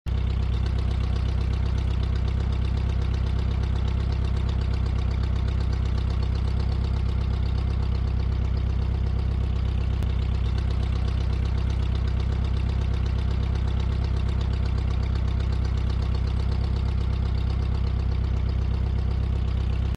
Звуки автомойки